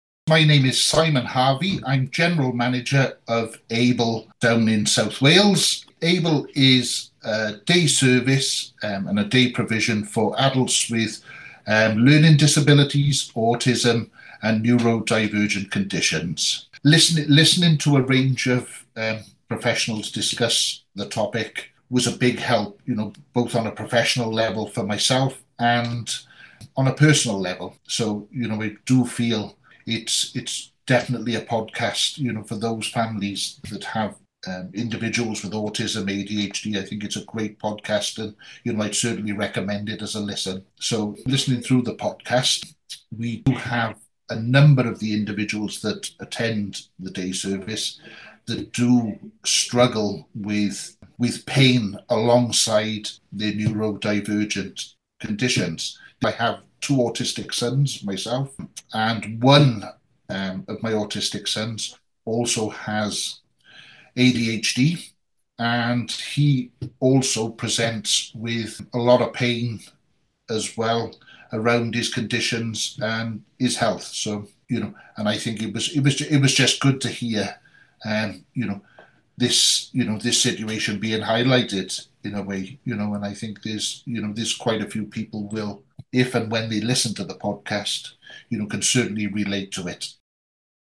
Airing Pain - Interview